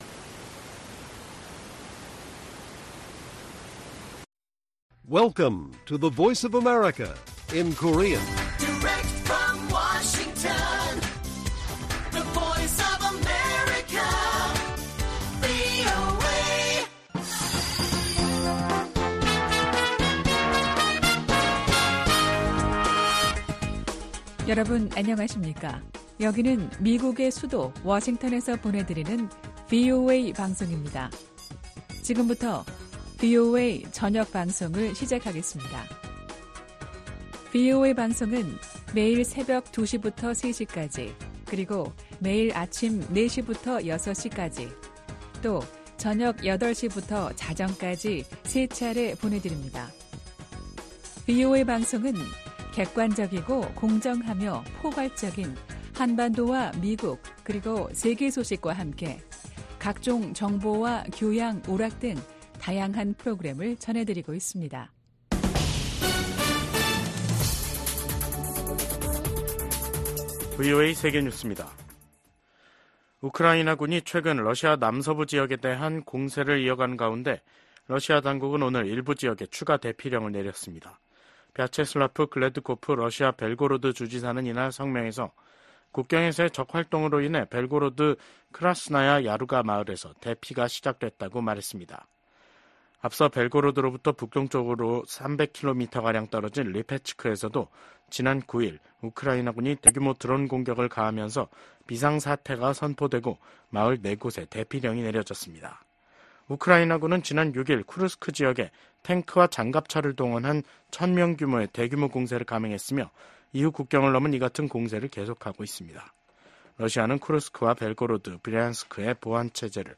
VOA 한국어 간판 뉴스 프로그램 '뉴스 투데이', 2024년 8월 12일 1부 방송입니다. 미국 국무부는 제재는 긴장만 고조시킨다는 중국의 주장에 새로운 대북제재 감시 체계 마련의 필요성을 지적했습니다. 한국 군 합동참모본부와 미한연합사령부는 오늘(12일) 국방부 청사에서 열린 공동 기자회견에서 올 하반기 미한 연합훈련인 ‘을지프리덤실드’(UFS) 연습을 이달 19일부터 29일까지 실시한다고 발표했습니다.